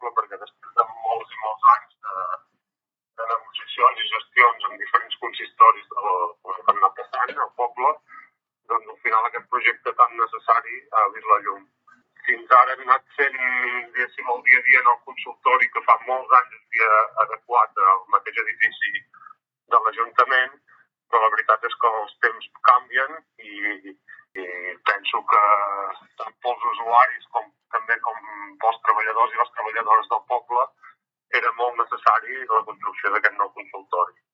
L’alcalde de Verges, Ignasi Sabater, explica que l’obertura d’aquest nou consultori és una gran notícia pel poble perquè era una necessitat des de feia molts anys.